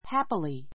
happily A2 hǽpili ハ ピり 副詞 ❶ 幸福に, 楽しく, 愉快 ゆかい に They lived happily together.